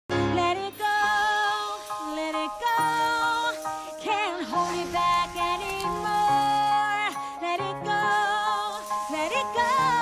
The iconic chorus